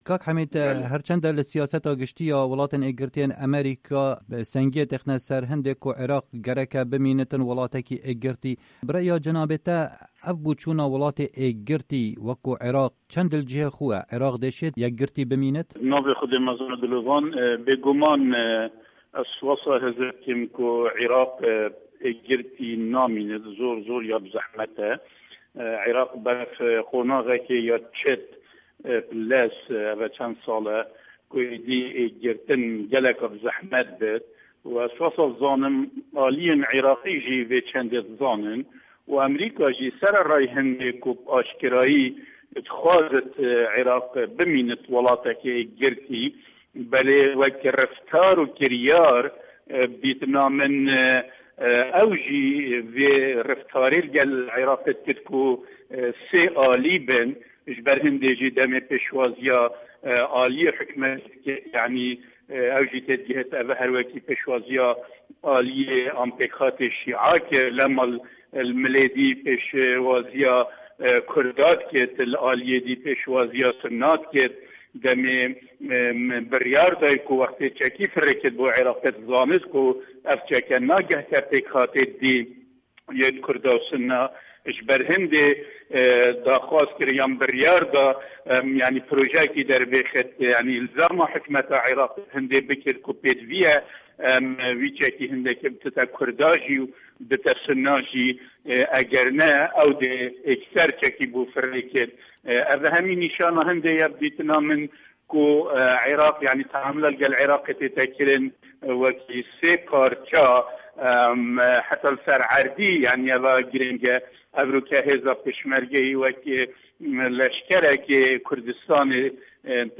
Di hevpeyvînekê de ligel Dengê Amerîka, Dr.Hemîd Adil siyasetvan û endamê berê yê parlamena Îraqê dibêje, Îraq di rastiyê de ber bi parçebûnê ve diçe û Kurdan bingeheke bihêz heye.
Hevpeyvin digel Dr. Hemîd Adil